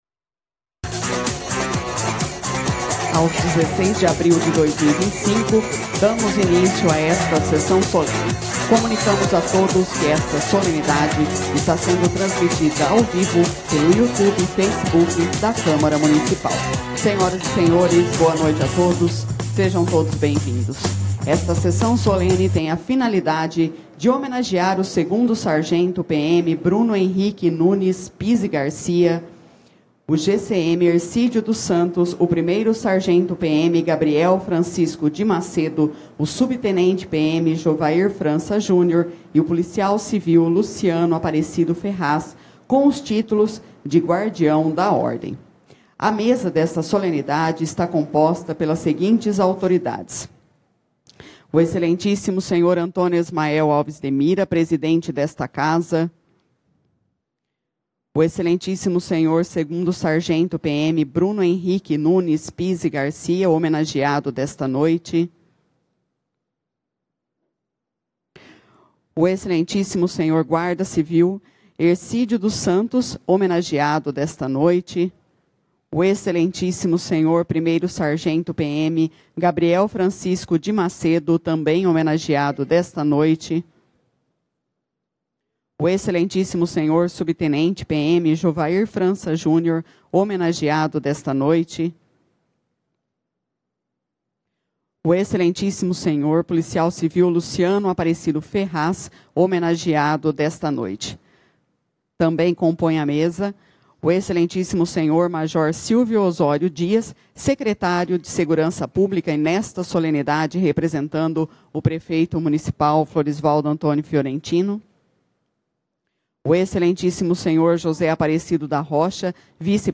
16/04/2025 Sessão Solene para a entrega do Título “Guardião da Ordem”.